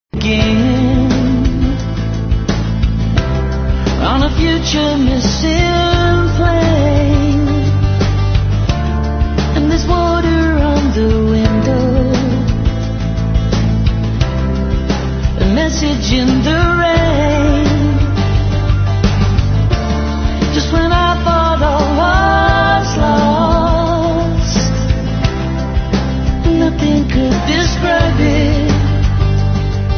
1. 00S ROCK >
マンチェスター出身ベテラン４人組バンドのNEWシングル！透明感とスケールに満ちた感動的な名曲！